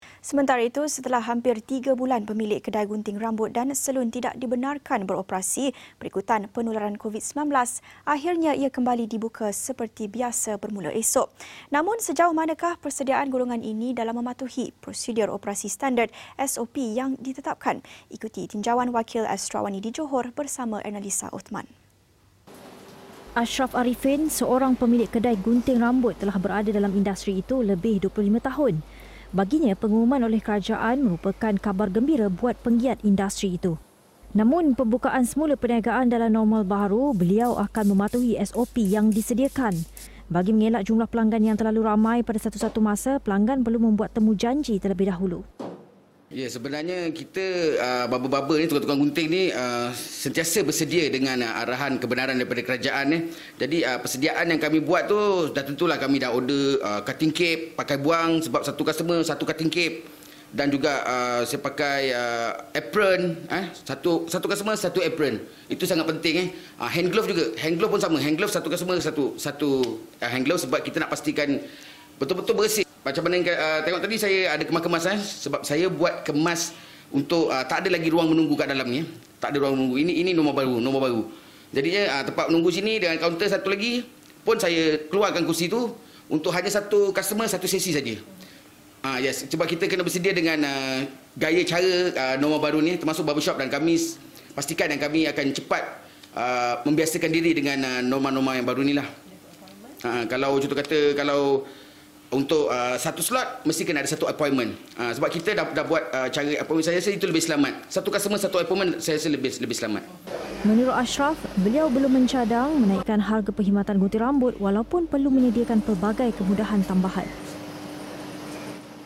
Ikuti tinjauan wakil Astro AWANI di Johor